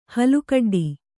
♪ halukaḍḍi